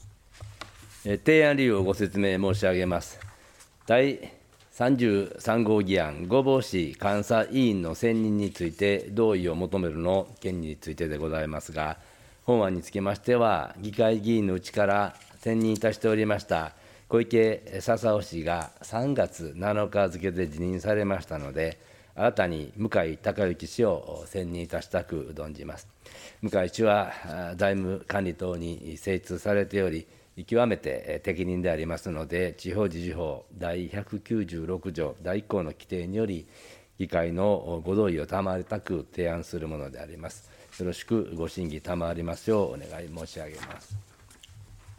市長の提案理由（第33号議案、第34号議案）